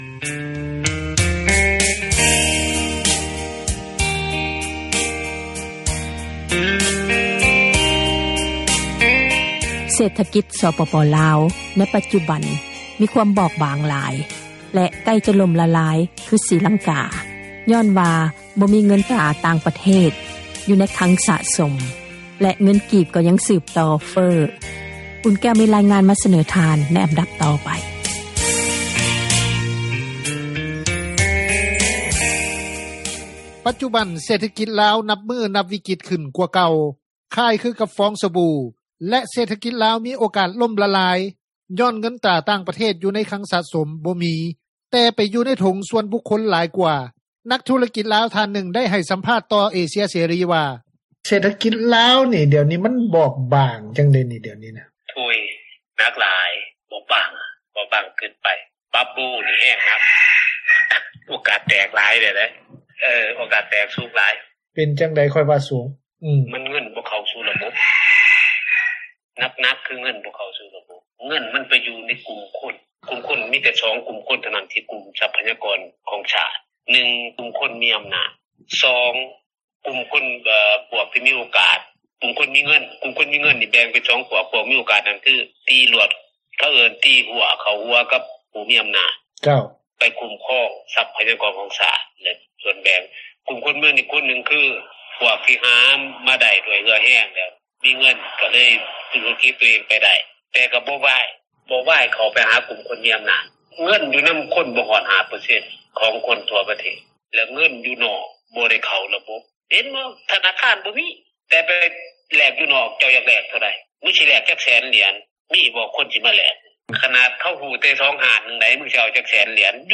ນັກທຸຣະກິຈທ່ານນຶ່ງ ໄດ້ໃຫ້ສັມພາດຕໍ່ວິທຍຸເອເຊັຽເສຣີ ວ່າ: